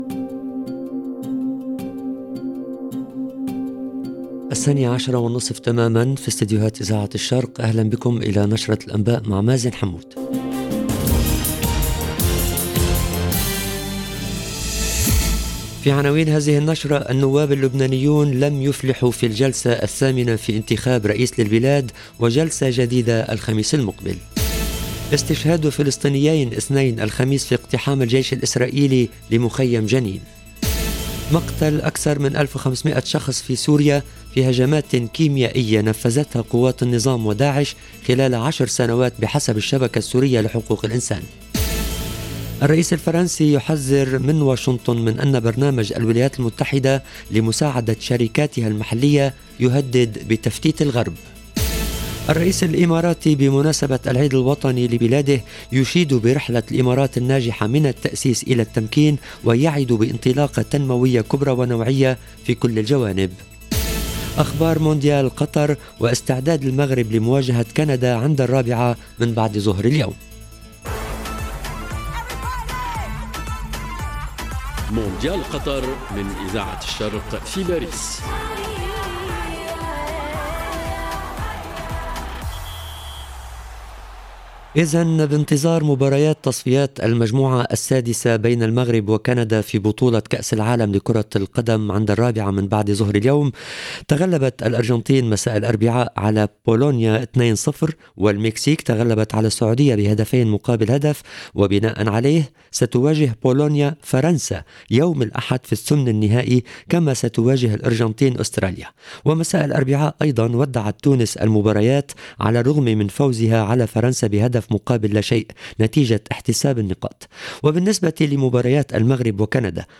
EDITION DU JOURNAL DE 12H30 EN LANGUE ARABE DU 1/12/2022